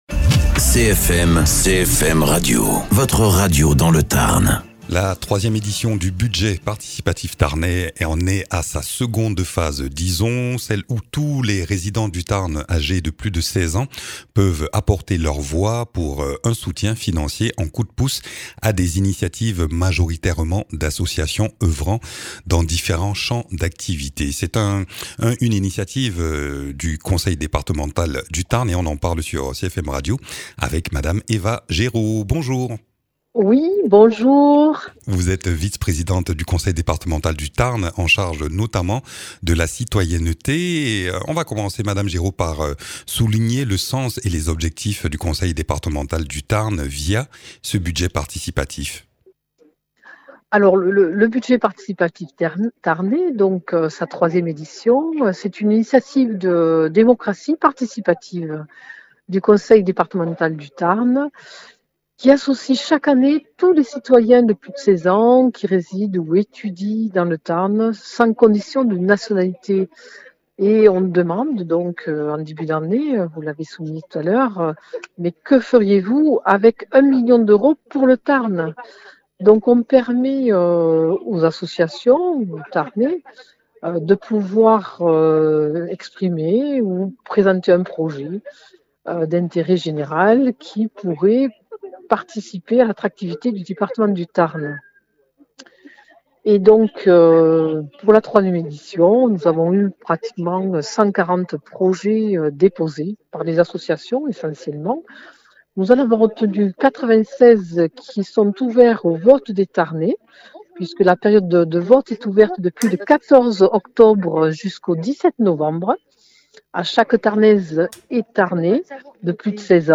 Interviews
Invité(s) : Eva Géraud, Vice-Présidente du Conseil Départemental du Tarn en charge de la citoyenneté.